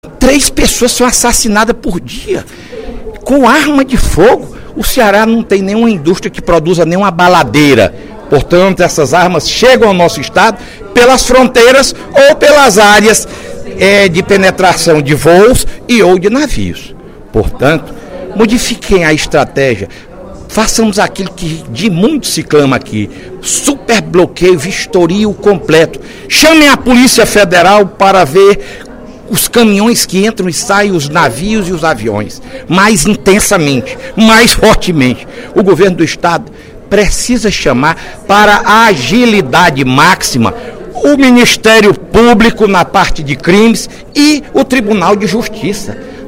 O deputado Fernando Hugo (PSDB) pediu, em pronunciamento no primeiro expediente da Assembleia Legislativa desta sexta-feira (08/03), um combate mais efetivo aos crimes de morte no Ceará.